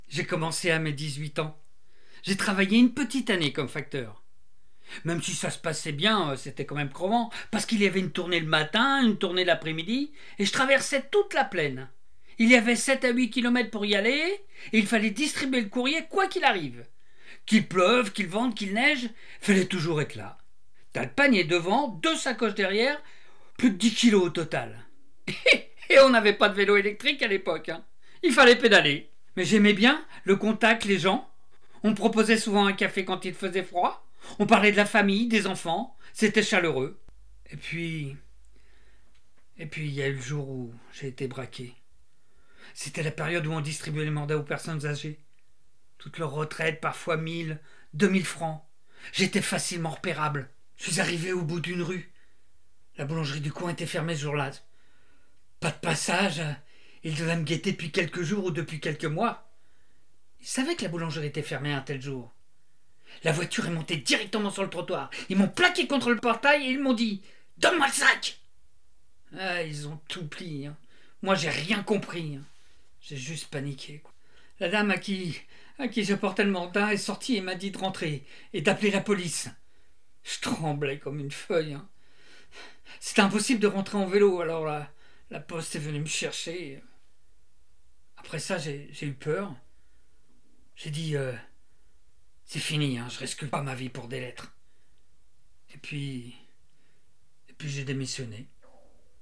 Interview Le facteur
36 - 60 ans - Ténor